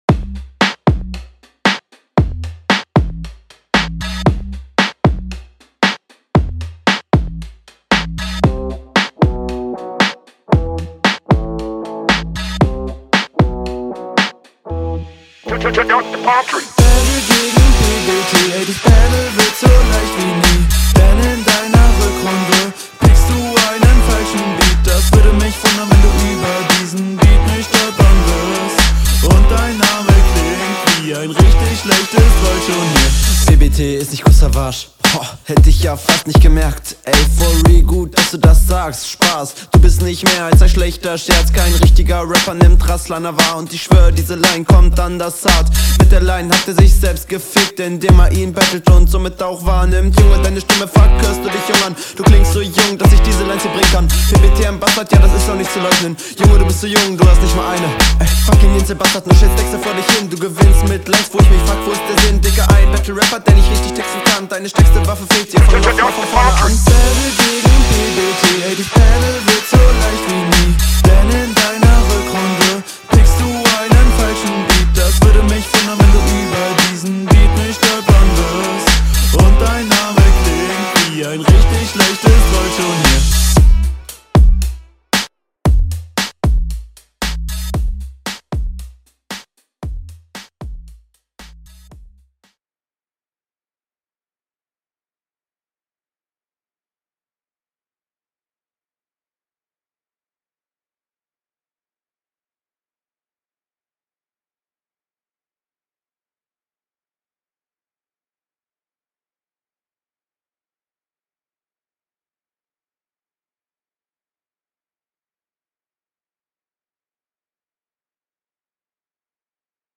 Hook macht Bock, aber ist aufgrund der leisen und zerrigen Mische nicht besonders verständlich.